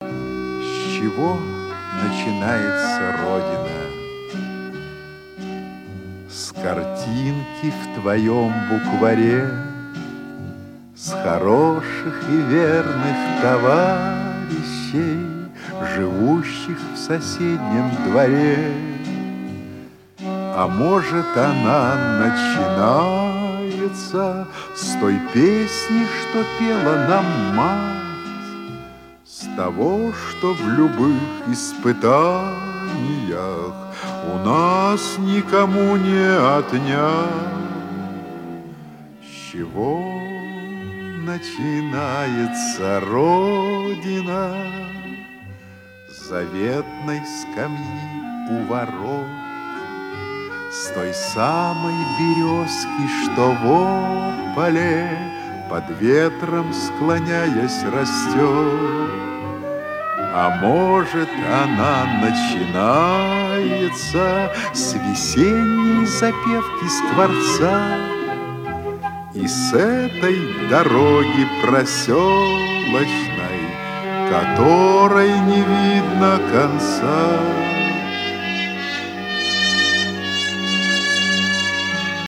эстрада